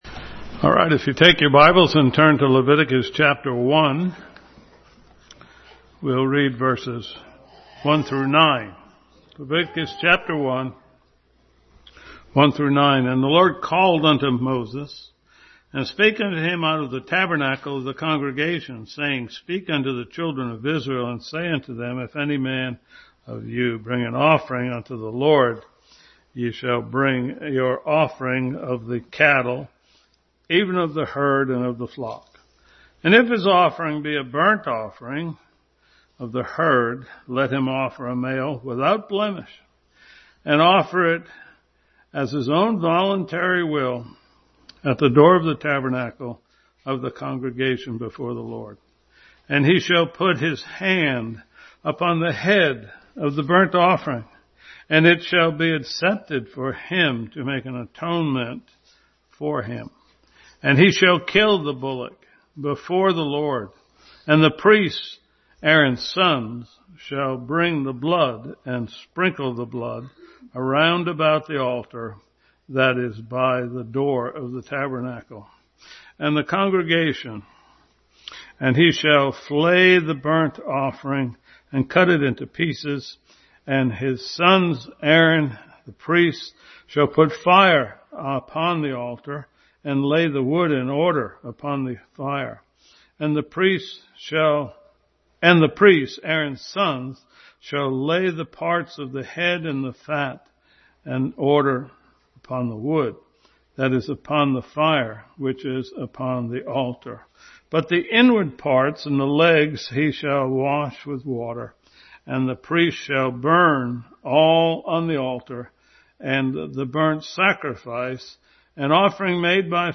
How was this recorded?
The Burnt Offerings Passage: Leviticus 1:1-9, 6:12, Genesis 4, 8:21, 22:3-14, Hebrews 11:4, Exodus 10:25 Service Type: Family Bible Hour